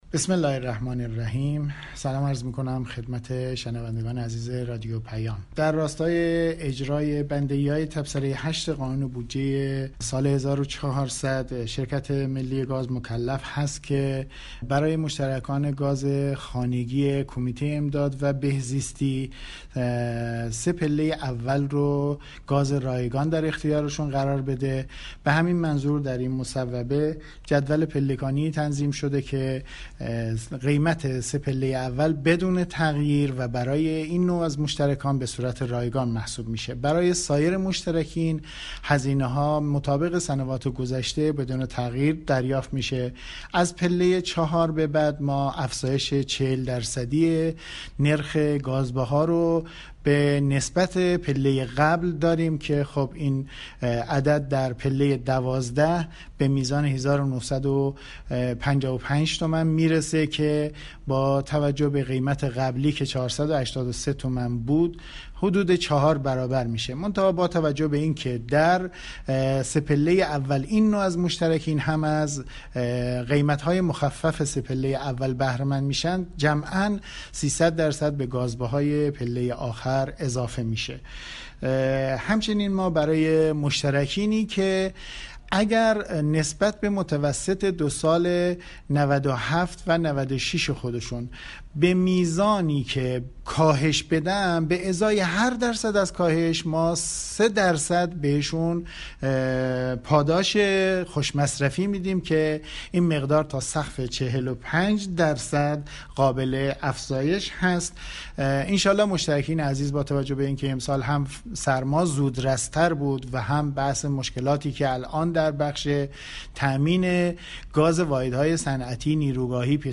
در گفتگو با سایت رادیو پیام